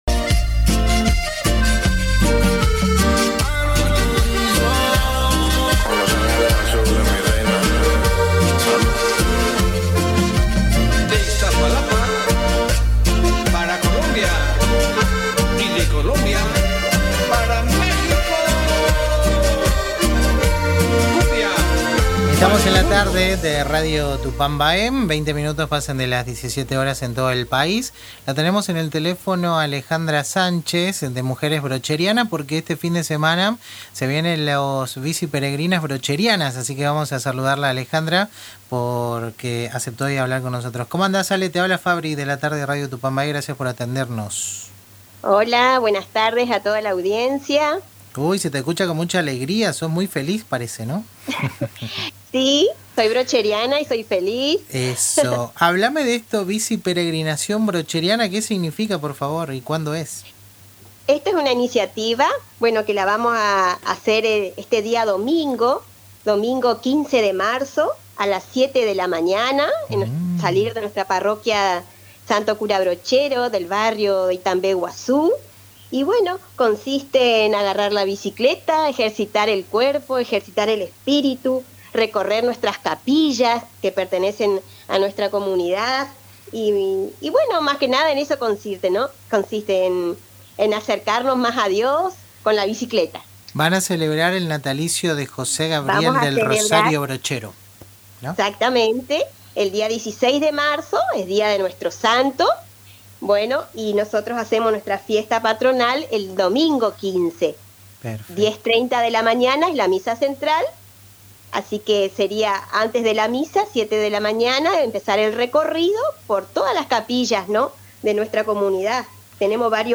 En diálogo con El Ritmo Sigue por Radio Tupambaé